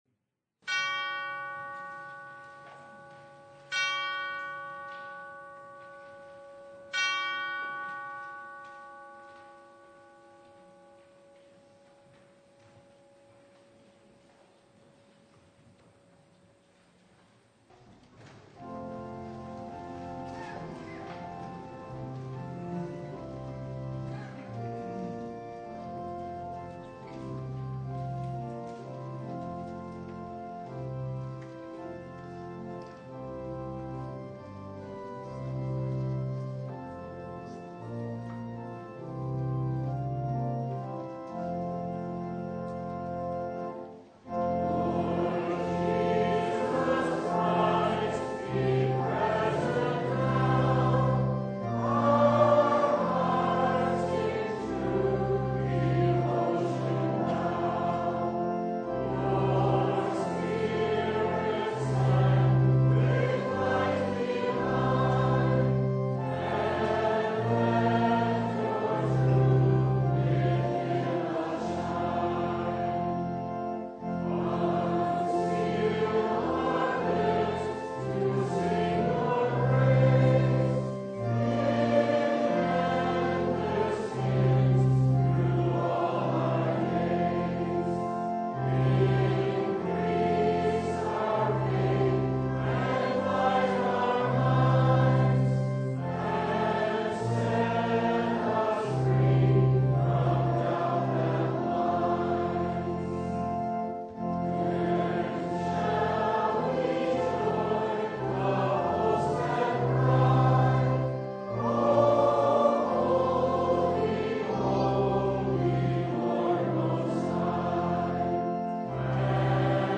Luke 17:1-10 Service Type: Sunday Temptations to sin and causes of offense are inevitable in our world.